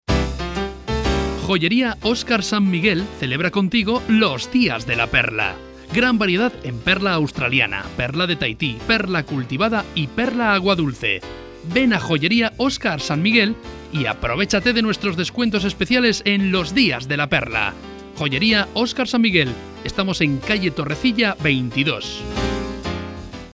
Anuncio de radio